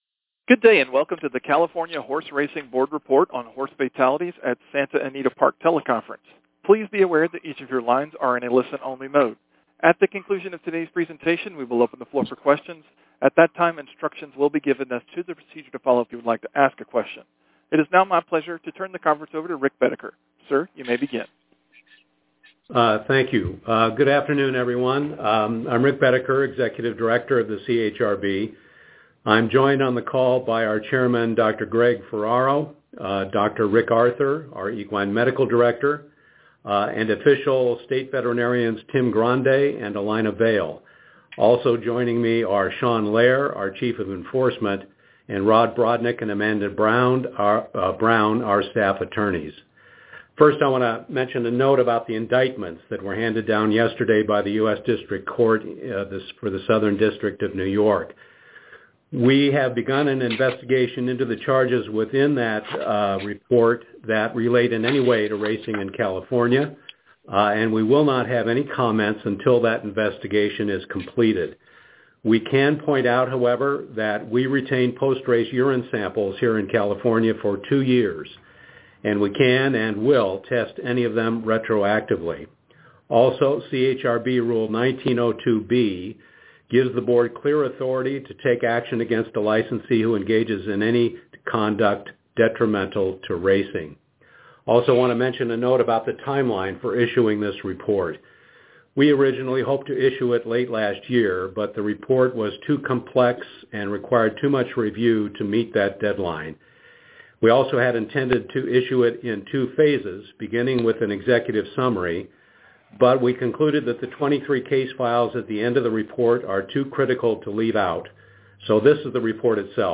Audio News Conference